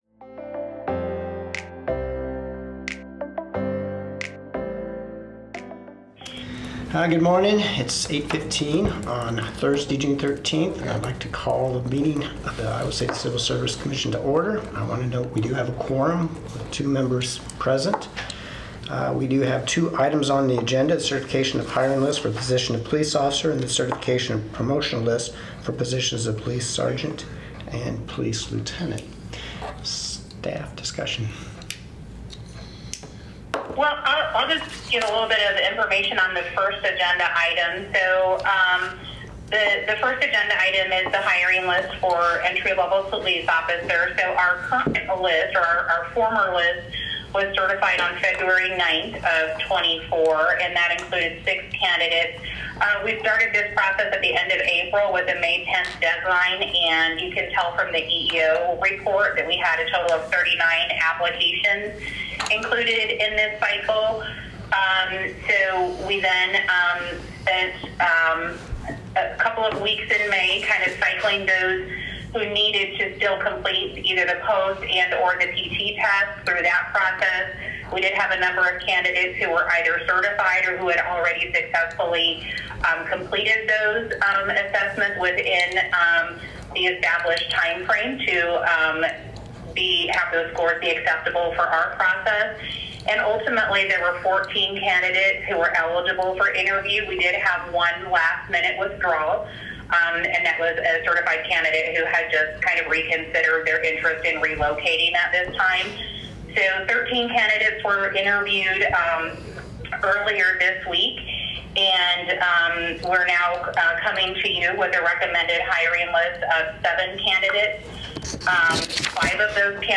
A meeting of the City of Iowa City's Civil Service Commission.